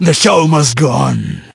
el_primo_hurt_04.ogg